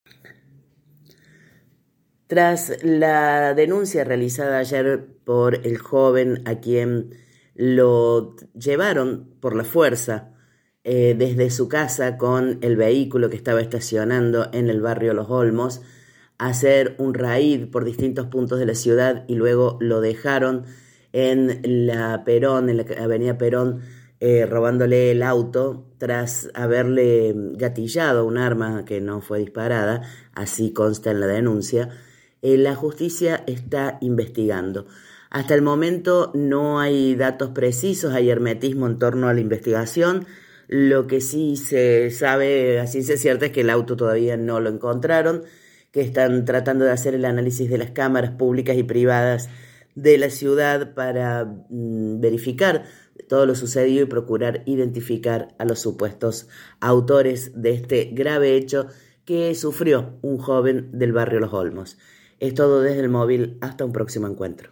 Audio: Informe